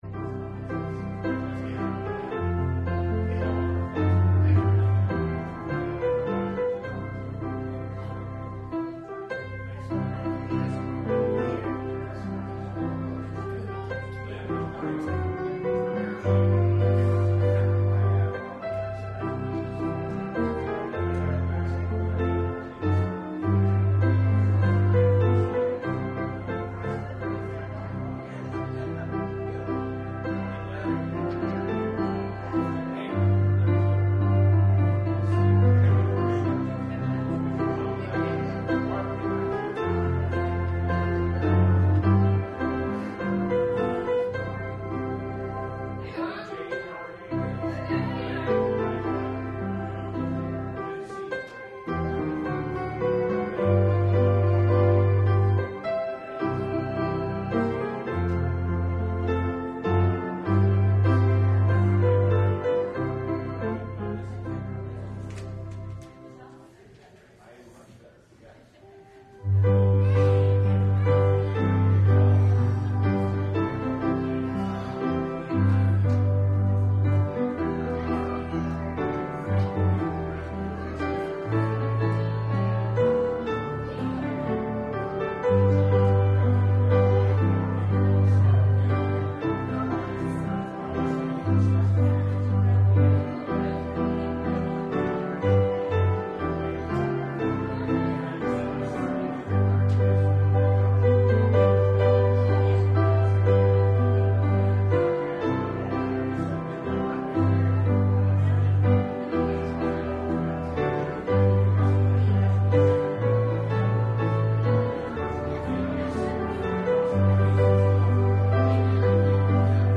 Service Type: Sunday Morning Service Topics: Christian Living , Giving , Offerings , Tithes